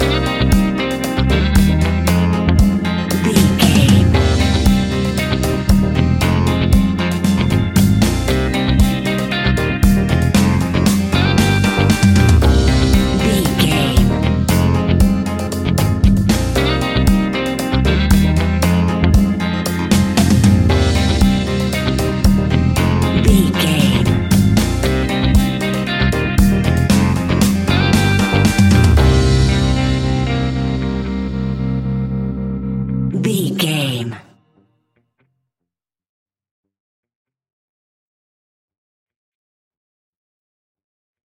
Aeolian/Minor
laid back
chilled
off beat
drums
skank guitar
hammond organ
percussion
horns